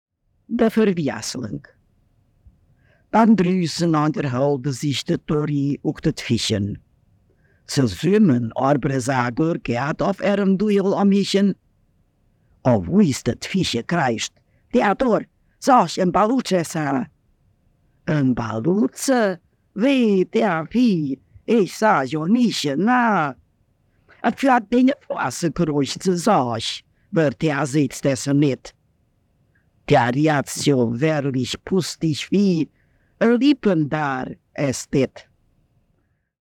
Ballutsch (Betonung auf der 2. Silbe) – Regenwurm (mancherorts)
Ortsmundart: Denndorf